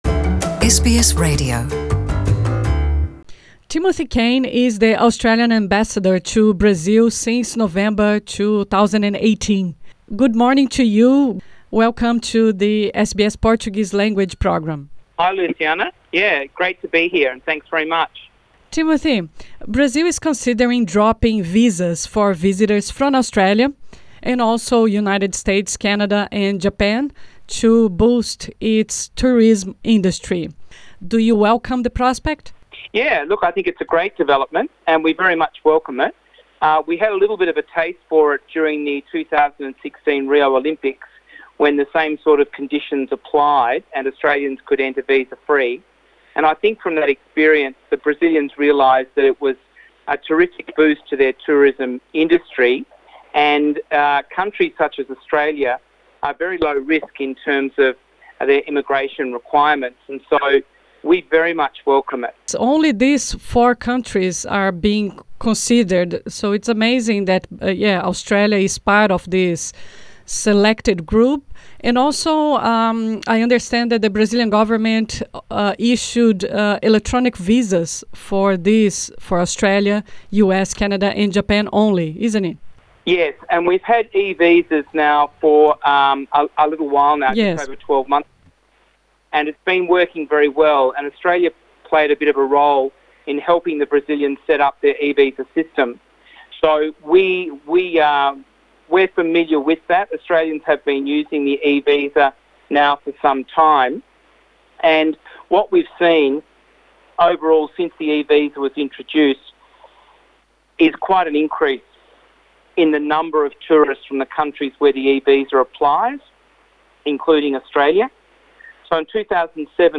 Nessa entrevista (gravada em inglês) ele fala também do recente anúncio feito pelo governo brasileiro que planeja dar isenção completa de vistos para turistas da Austrália.